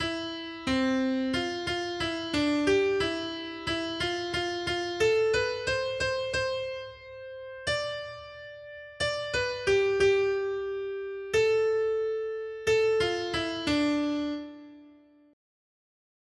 Noty Štítky, zpěvníky ol145.pdf responsoriální žalm Žaltář (Olejník) 145 Skrýt akordy R: Jděte do celého světa a hlásejte evangelium. 1.